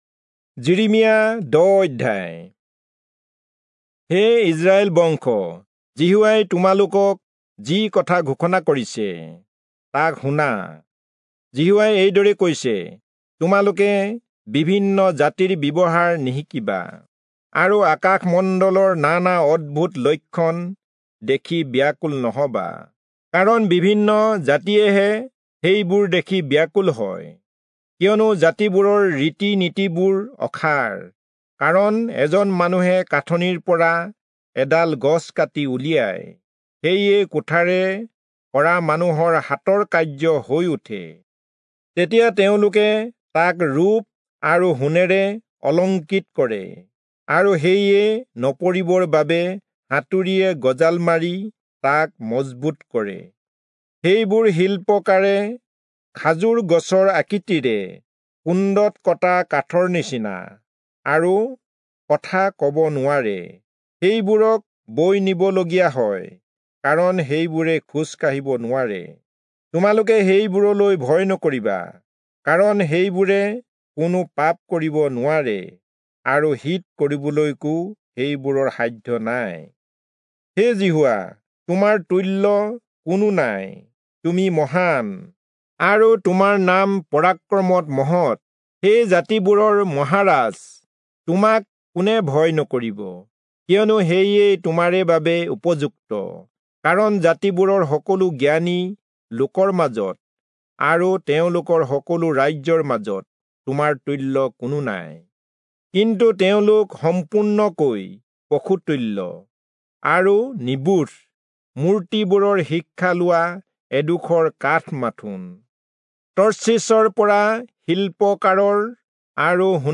Assamese Audio Bible - Jeremiah 32 in Ervte bible version